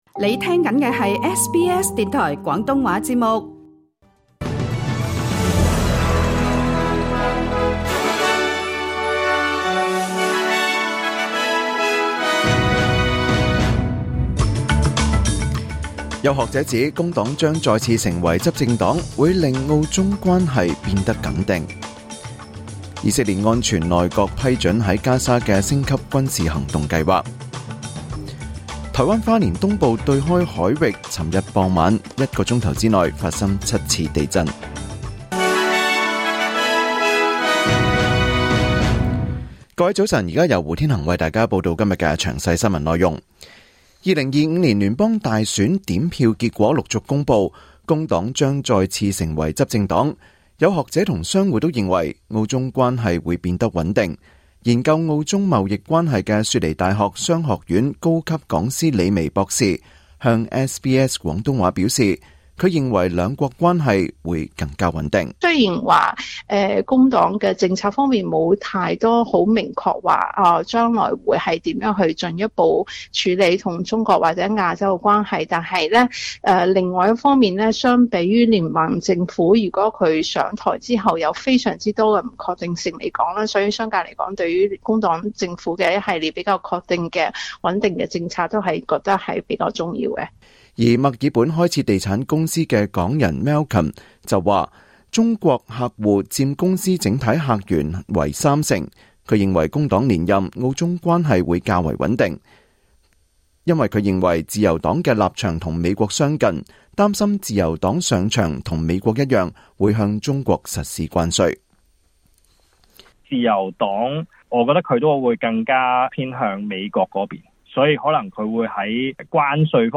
2025年5月6日SBS 廣東話節目九點半新聞報道。